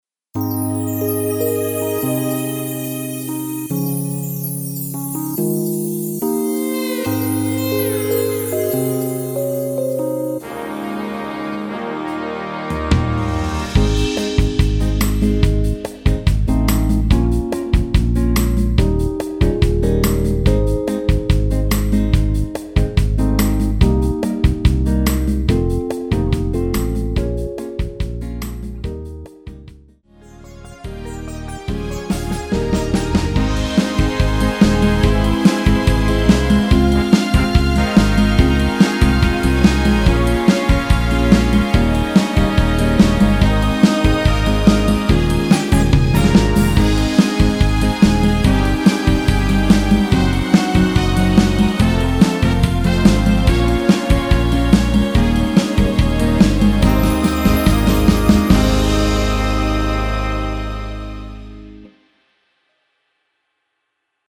MR 입니다.
Ab
◈ 곡명 옆 (-1)은 반음 내림, (+1)은 반음 올림 입니다.
앞부분30초, 뒷부분30초씩 편집해서 올려 드리고 있습니다.
중간에 음이 끈어지고 다시 나오는 이유는